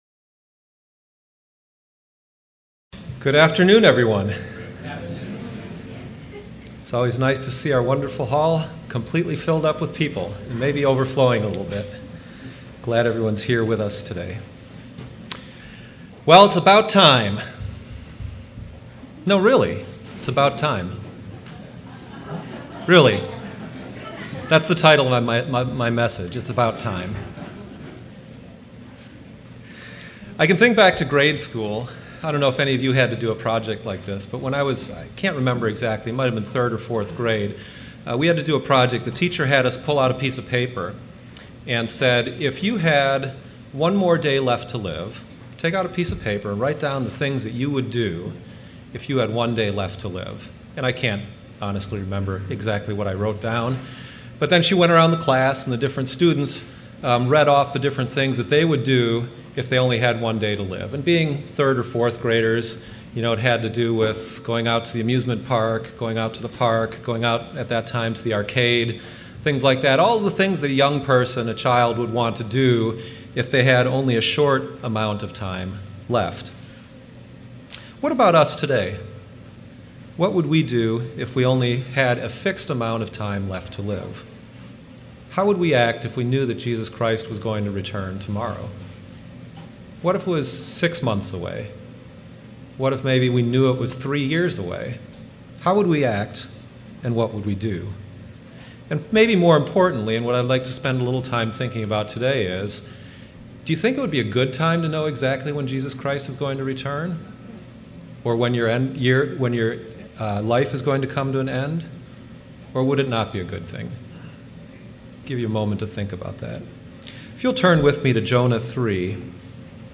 Do we understand how long-suffering relates to using time wisely. This Feast of Trumpets let's study the importance of redeeming the time effectively.